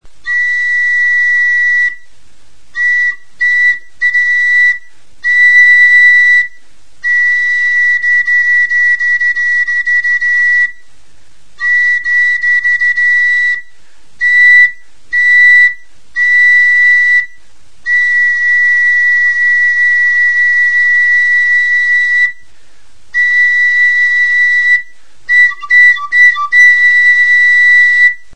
TXULUBITA | Soinuenea Herri Musikaren Txokoa
Instruments de musique: TXULUBITA Classification: Aérophones -> Flûtes -> Á Bec (á une main) Emplacement: Erakusketa; hots-jostailuak Explication de l'acquisition: Emana; egileak emana. Description: Gaztain makilarekin egindako txulubita da.